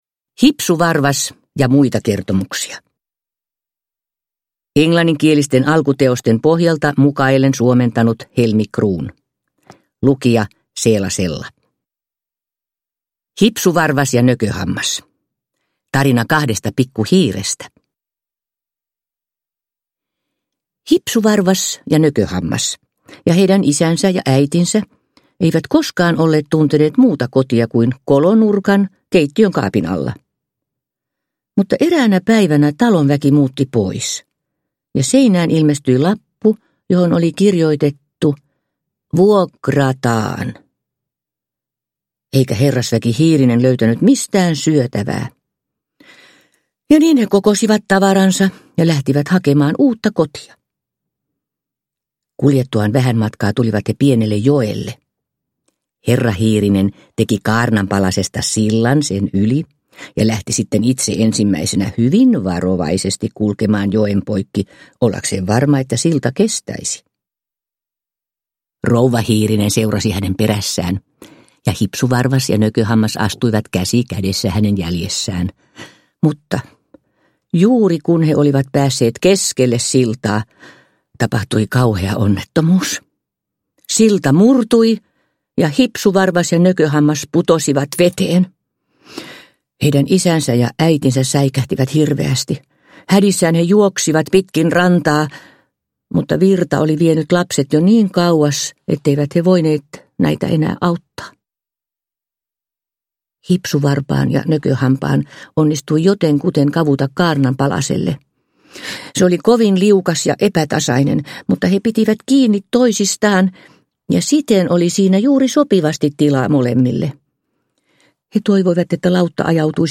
Hipsuvarvas ja muita kertomuksia – Ljudbok – Laddas ner
Uppläsare: Seela Sella